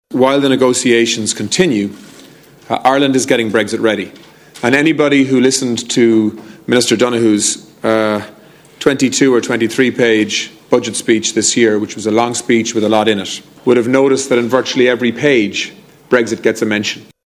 Tanaiste Simon Coveney says all these measures are to insulate Ireland from Brexit: